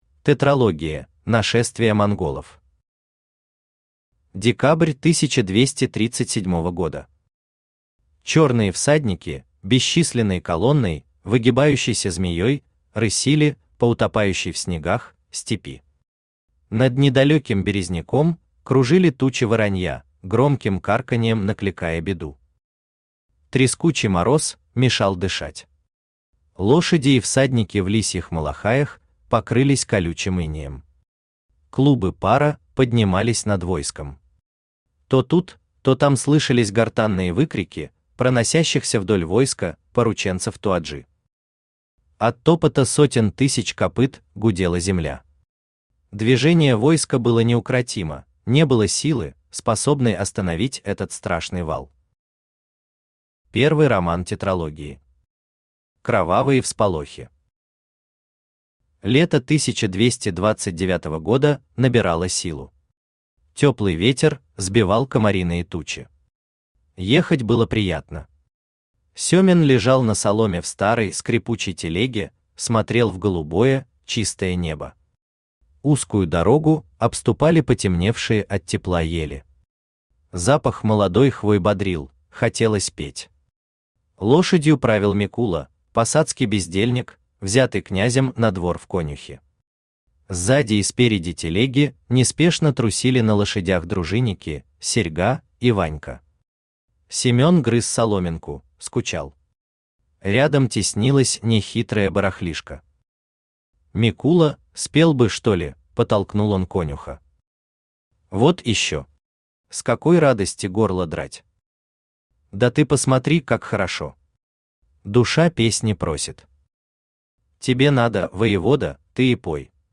Аудиокнига Русская Лолита! Кровавые всполохи | Библиотека аудиокниг
Aудиокнига Русская Лолита! Кровавые всполохи Автор Алексей Николаевич Наст Читает аудиокнигу Авточтец ЛитРес.